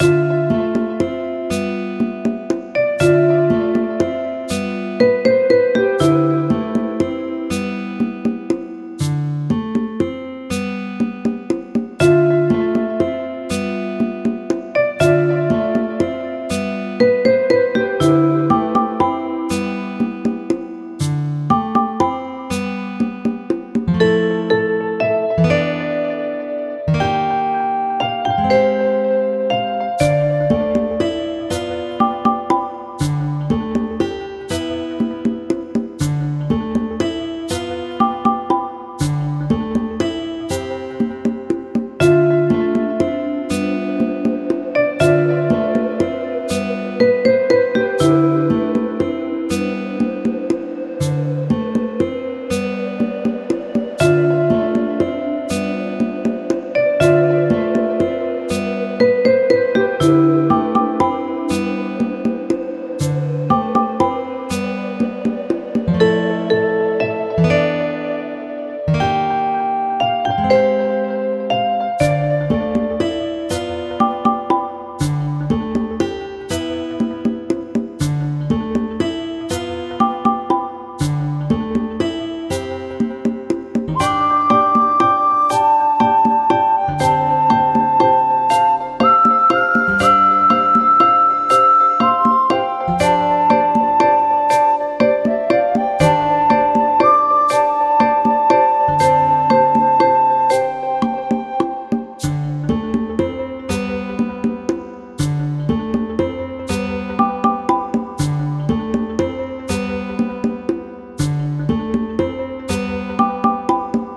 ゆったりした楽曲
【イメージ】森、不思議 など